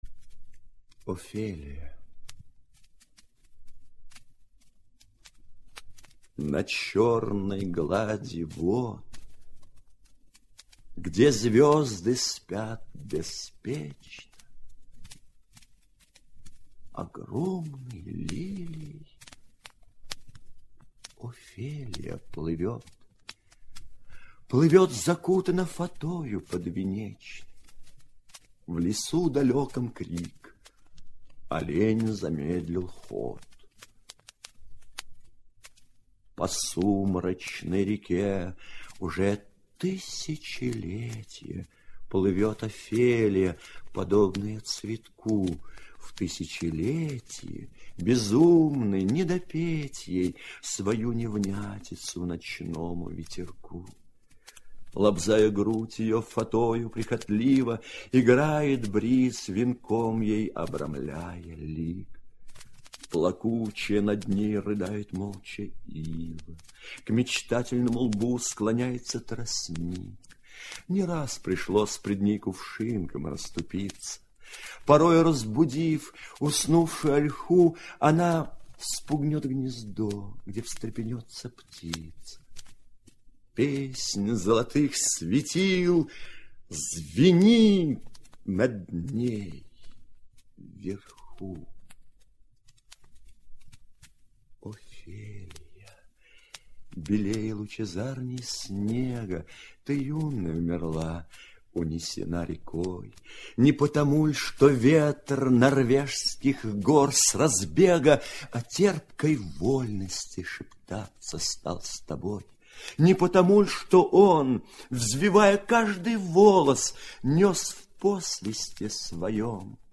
5. «Рембо Артюр – Офелия (читает Эдуард Марцевич) – 001» /